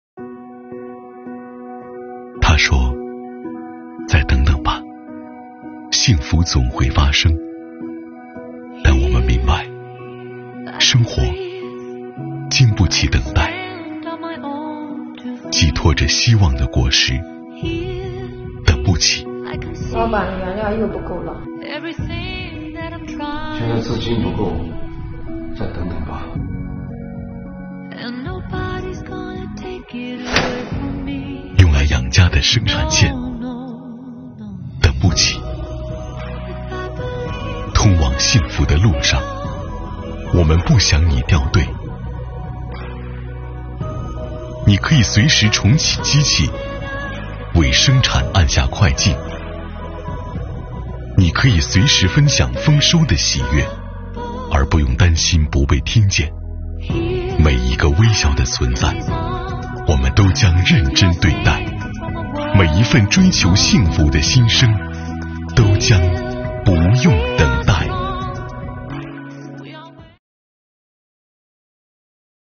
作品以枸杞种植户为原型，讲述宁夏税务部门全面落实税费优惠，扎实推进增值税留抵退税政策落实落细，为纳税人带去满满的幸福感和获得感。作品运镜流畅，画面丰富，清浅的背景音乐配合柔和的话外音，让读者耳目一新。